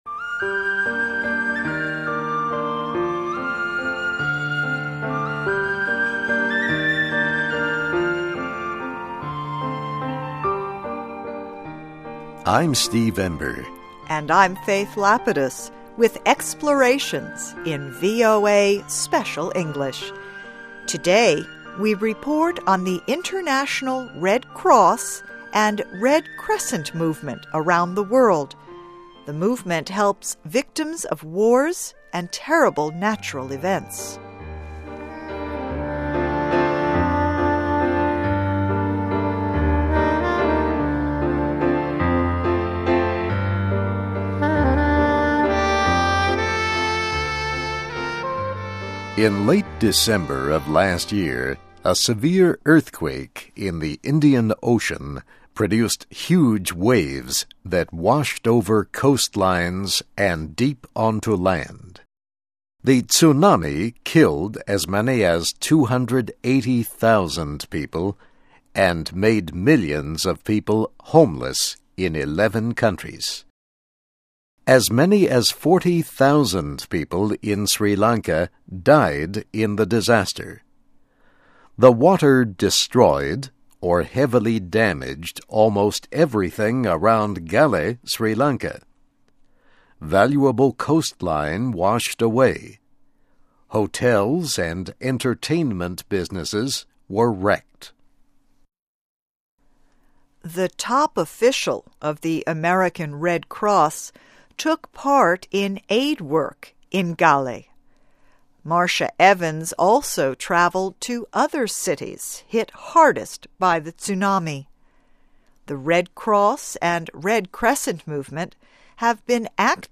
ESL, EFL, English Listening Practice, Reading Practice